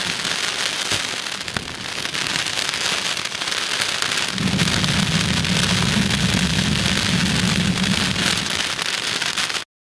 Звук костра:
fire4.wav